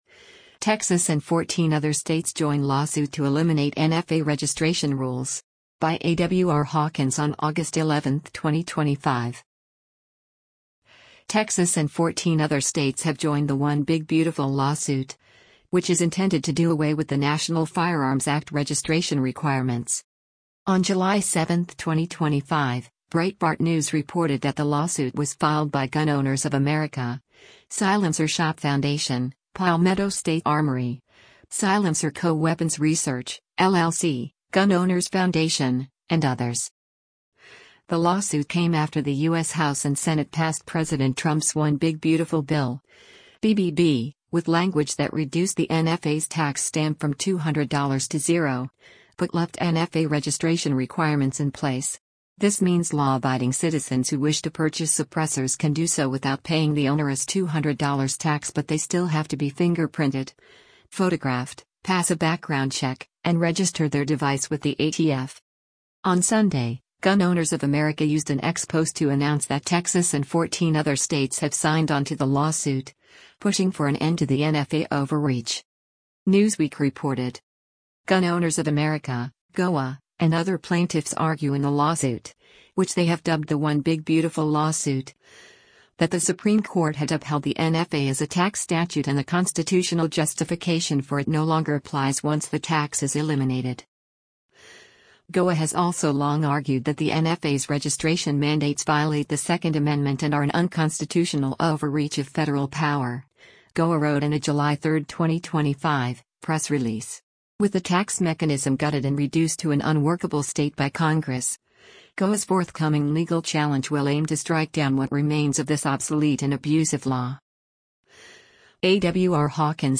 shooting with a suppressor